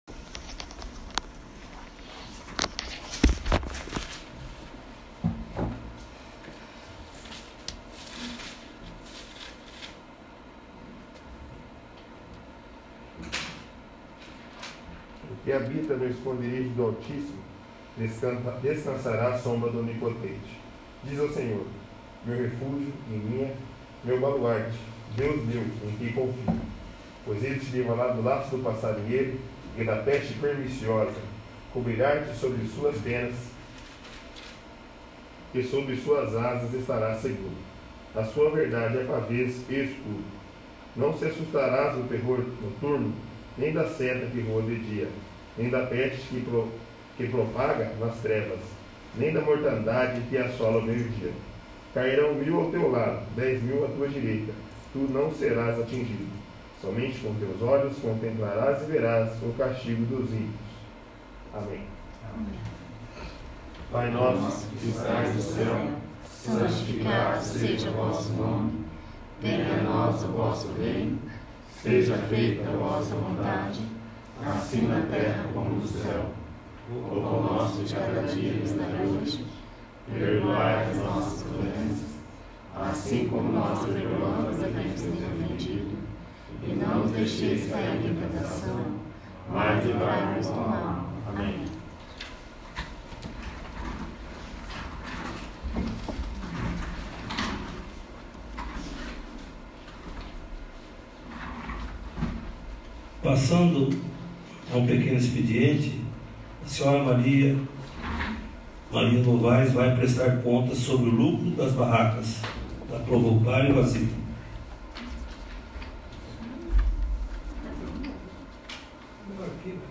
14º. Sessão Ordinária